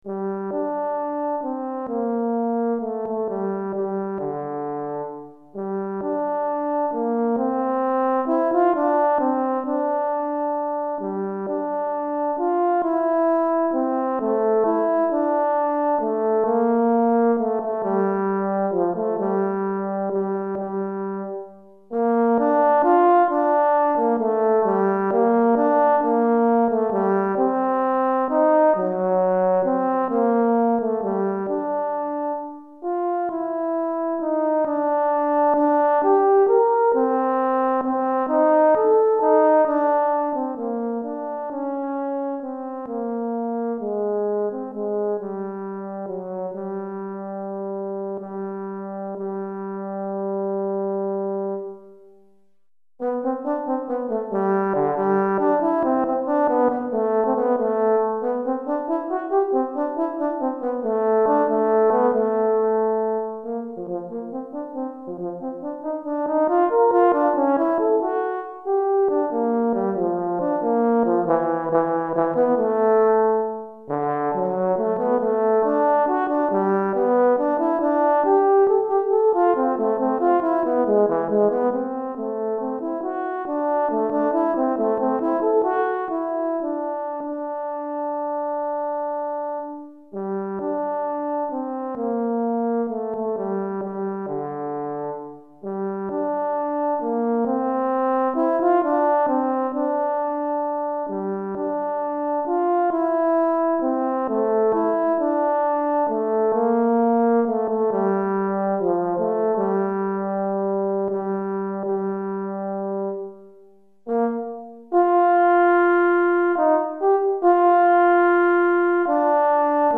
Pour cor solo DEGRE CYCLE 2
Cor solo